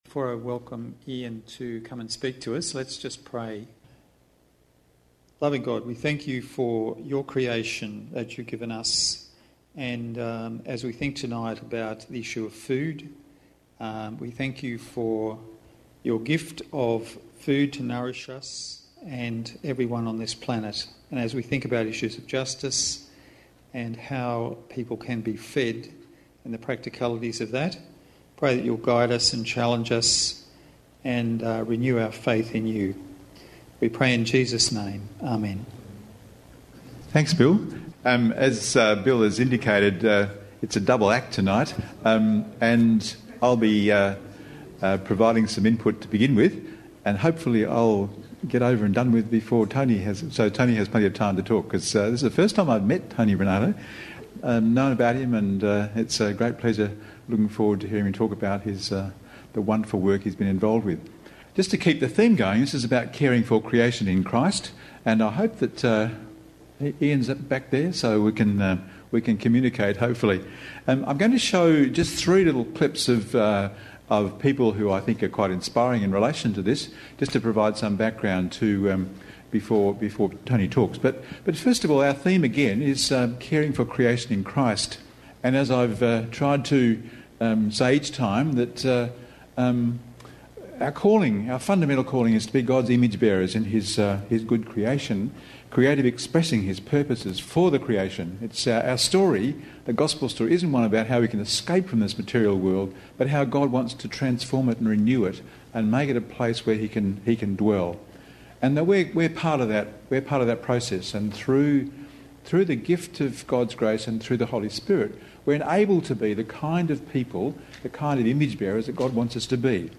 [post_id=1269] From this series Current Sermon Growing food for a hungry planet Creation Care Guest Speaker September 10, 2014 View all Sermons in Series